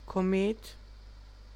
Ääntäminen
Synonyymit faxed star Ääntäminen US : IPA : [ˈkɒ.ət] Tuntematon aksentti: IPA : /ˈkɒ.mət/ Haettu sana löytyi näillä lähdekielillä: englanti Käännös Ääninäyte Substantiivit 1.